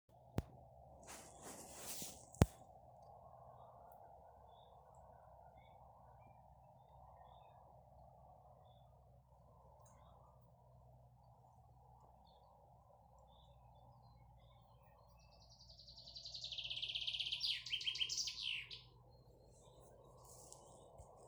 Žubīte, Fringilla coelebs
Ziņotāja saglabāts vietas nosaukumsBauskas nov Vecumnieku pag.
StatussDzied ligzdošanai piemērotā biotopā (D)